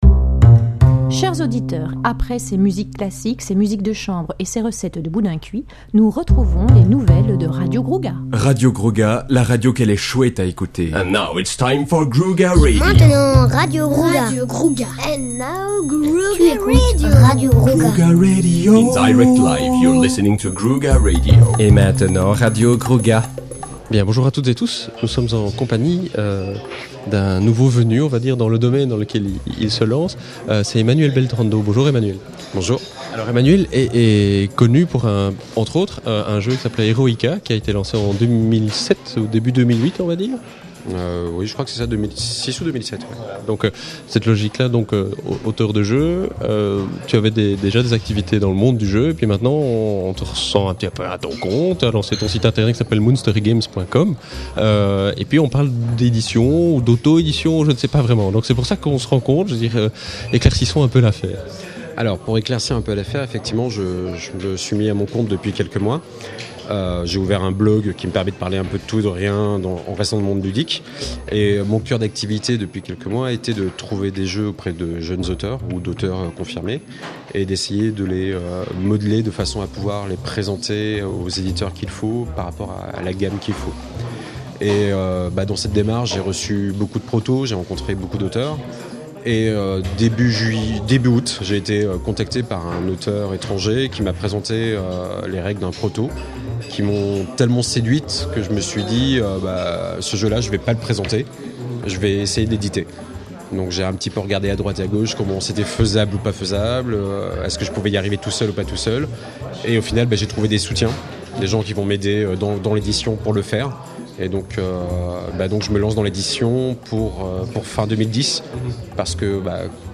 (enregistré lors du salon international du jeu de société Spiel 2009 à Essen/Allemagne)